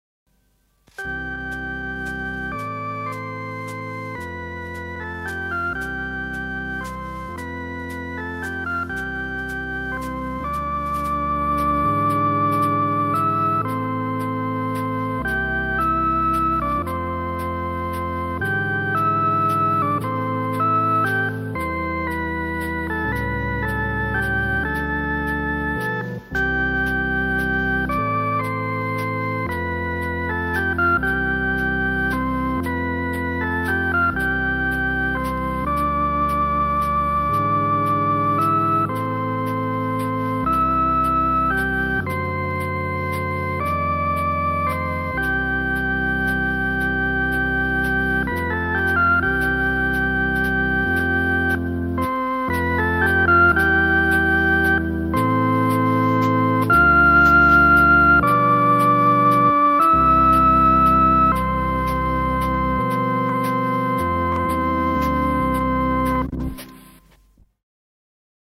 Philicorda